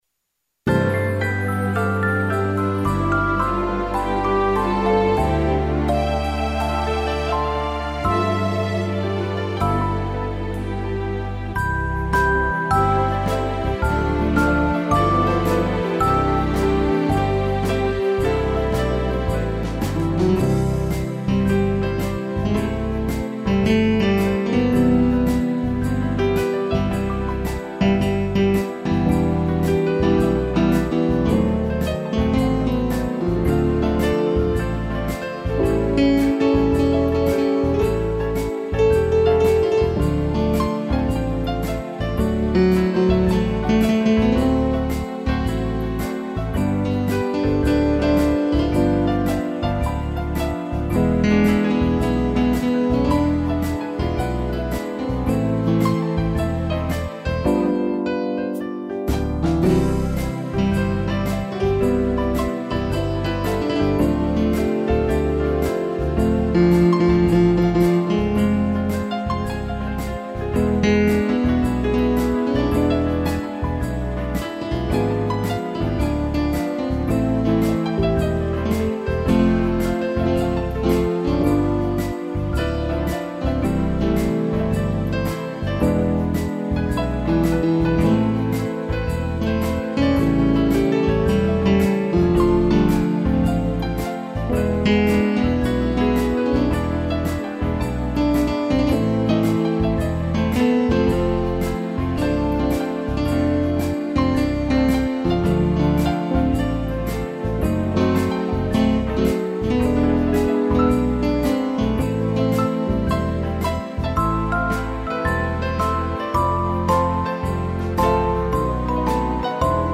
piano e pad
(instrumental)